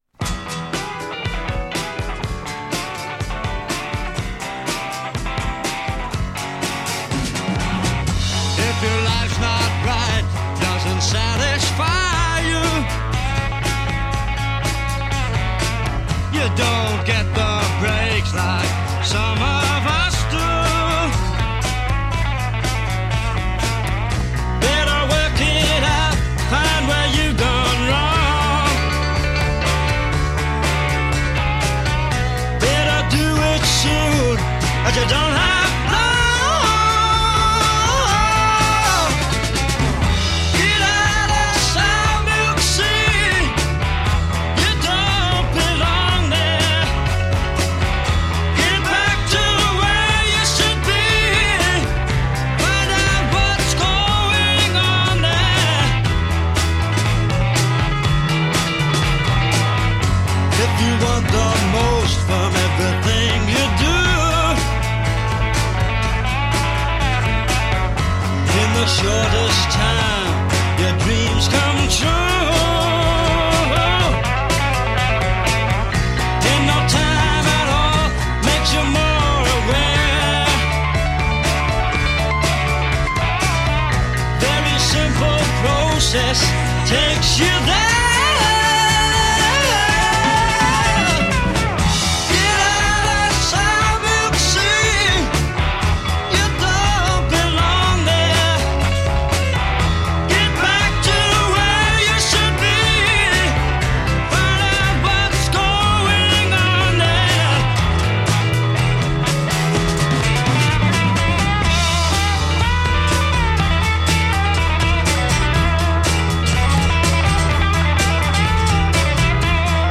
one of the most distinctive voices in Rock.